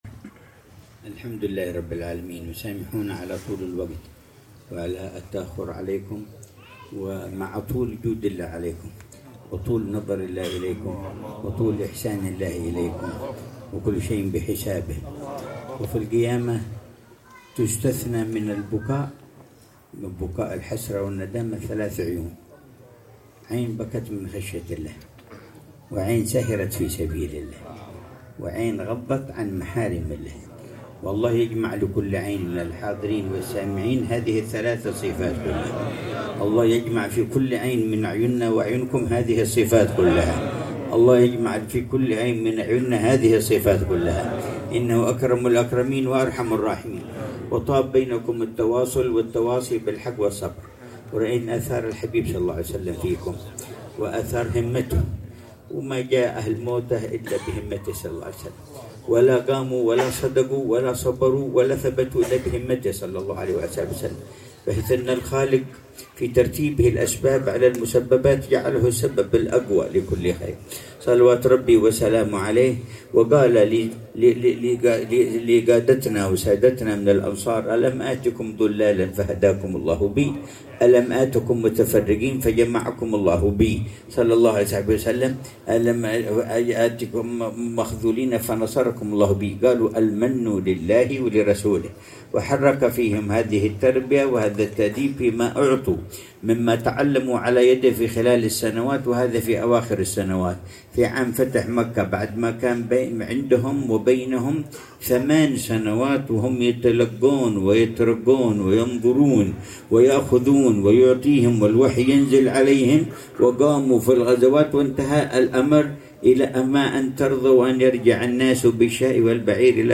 كلمة العلامة الحبيب عمر بن محمد بن حفيظ في ختام فعاليات الدورة العلمية في موسم مؤتة في دار الأنوار، في العاصمة الأردنية عمان، ليلة الثلاثاء 6 جمادى الأولى 1447هـ.